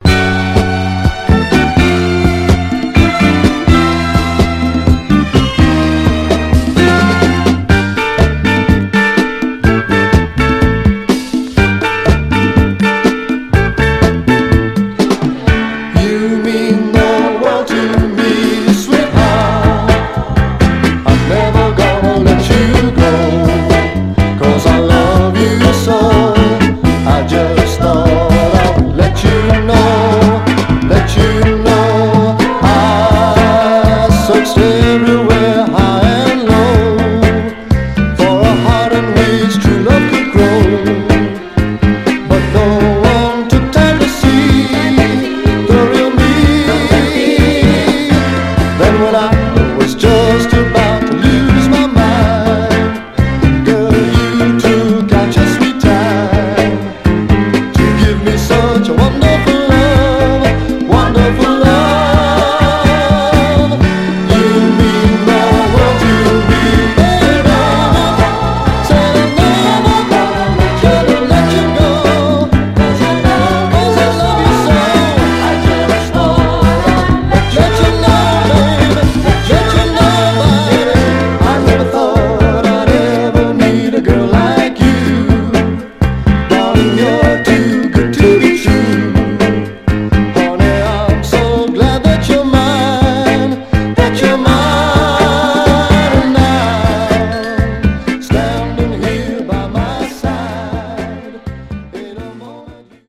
ブルーアイド・ソウル・シンガー
盤はステレオ面に二箇所短い線キズ箇所ありますが、音への影響は少なく全体的にグロスがありプレイ良好です。
※試聴音源は実際にお送りする商品から録音したものです※